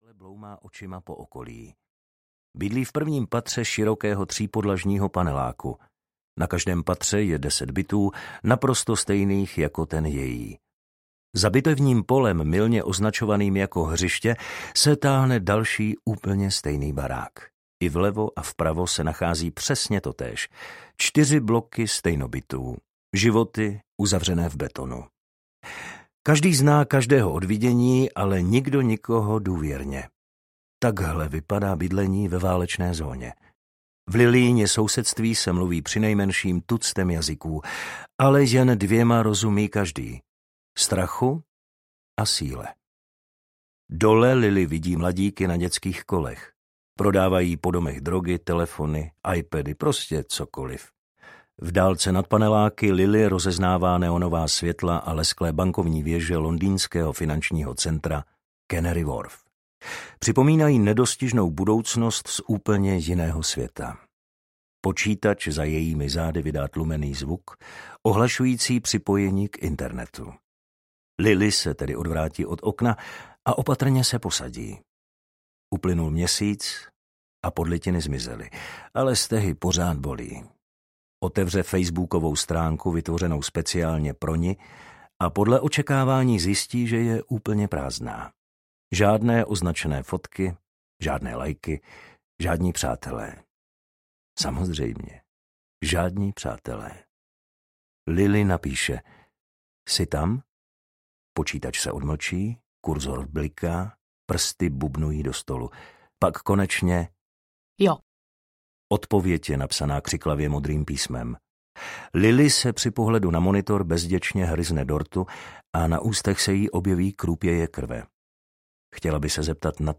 Dívka, která si říkala Tuesday audiokniha
Ukázka z knihy
divka-ktera-si-rikala-tuesday-audiokniha